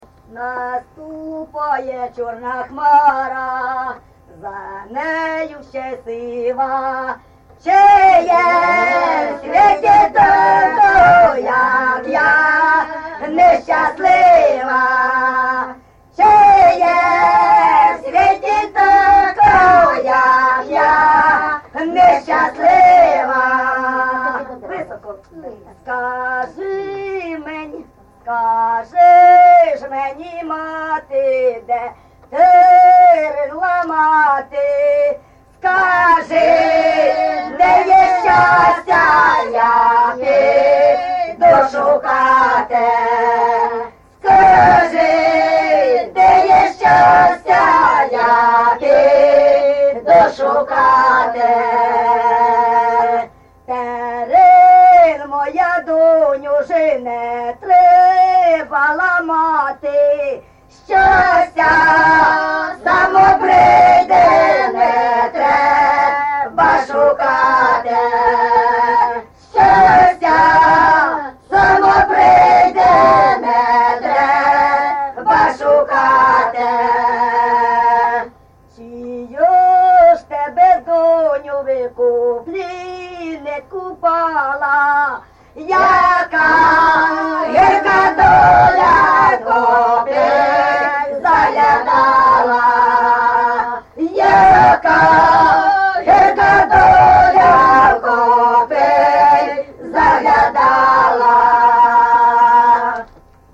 ЖанрПісні з особистого та родинного життя
Місце записус. Лука, Лохвицький (Миргородський) район, Полтавська обл., Україна, Полтавщина